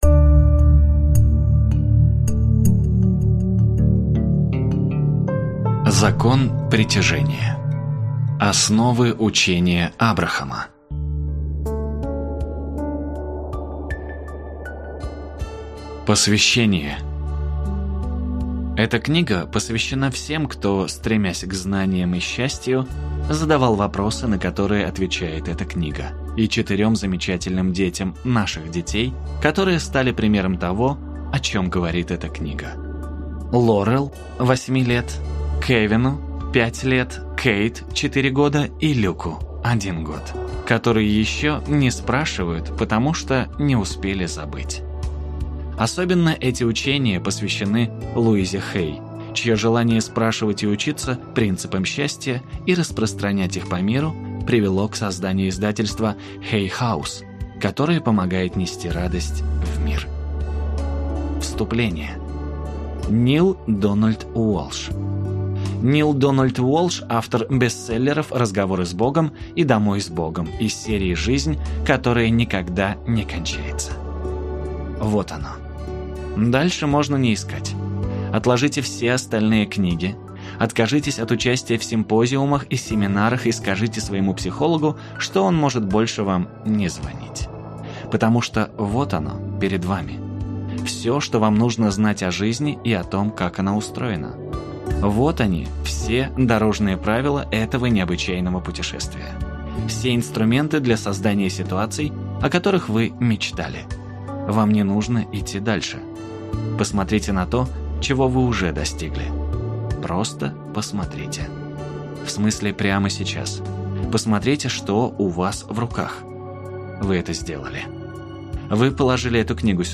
Аудиокнига Закон притяжения | Библиотека аудиокниг